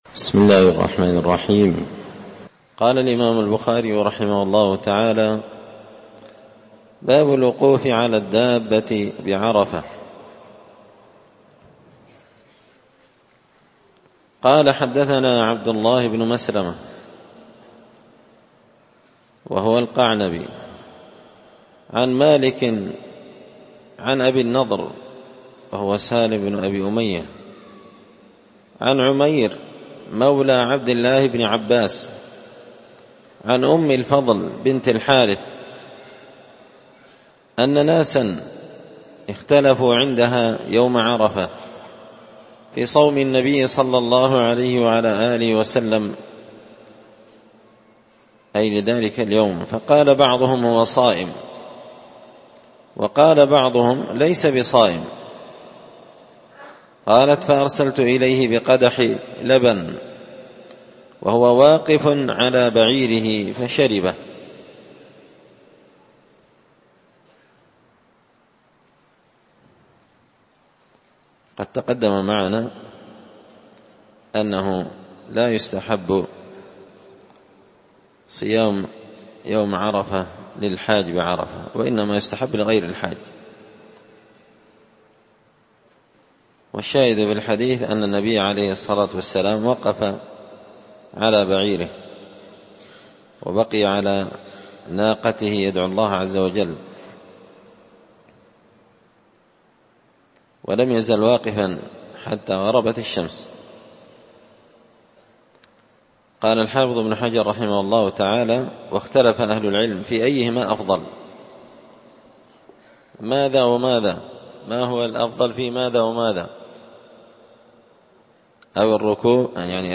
كتاب الحج من شرح صحيح البخاري – الدرس 79